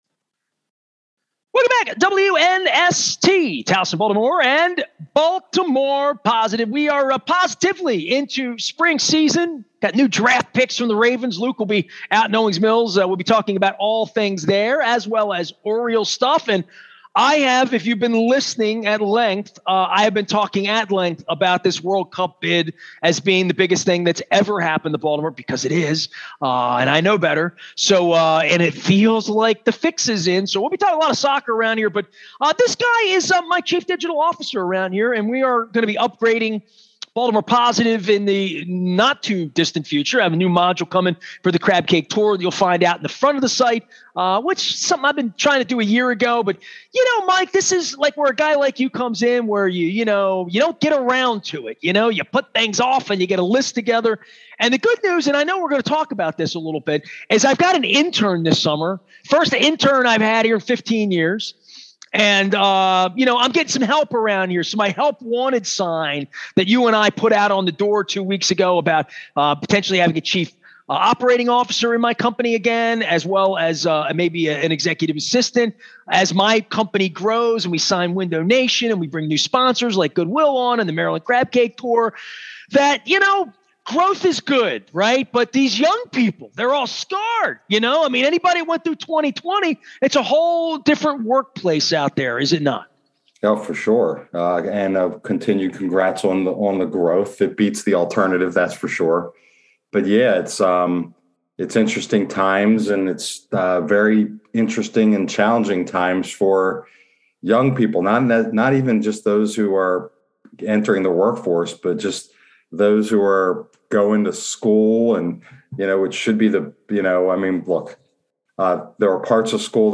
a spirited debate on free speech, Twitter and corruption of news